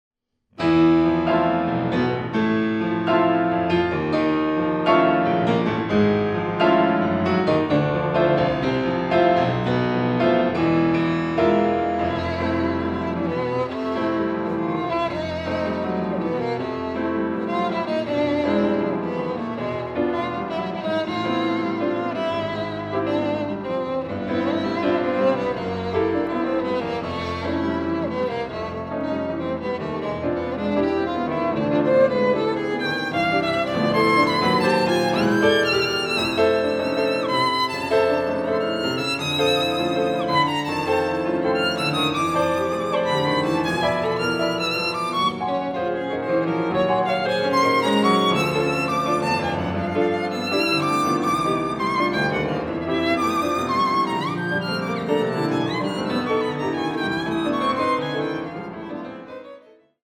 Intimo